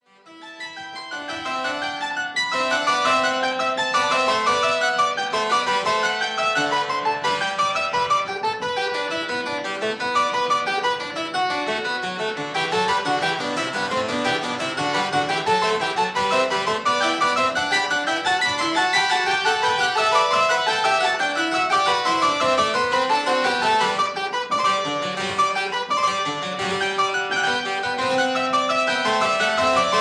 two manual harpsichord
(I: 16,8,4 leather; II: 8 quill, 8 lute)